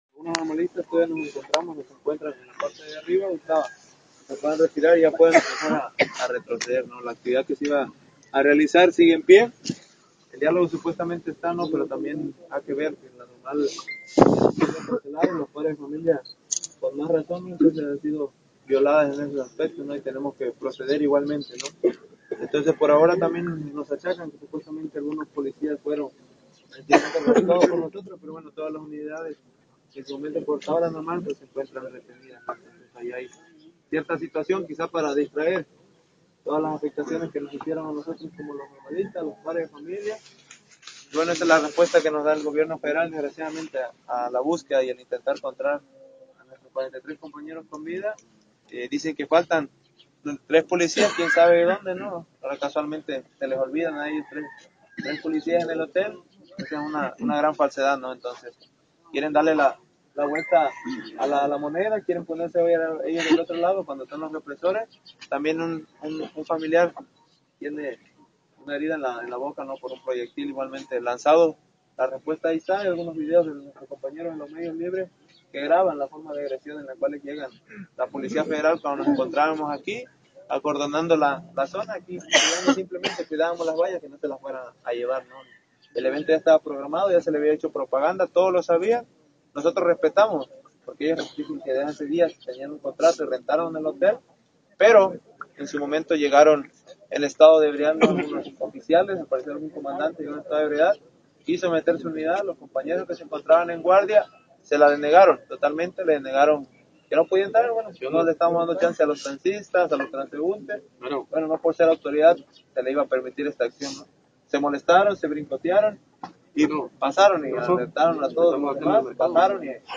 Un estudiante normalista señaló: “Esta es la respuesta que nos da el gobierno federal a la búsqueda y al intentar encontrar a 43 compañeros con vida”. También reportó que un familiar fue alcanzado en la boca por una granada de gas lacrimógeno: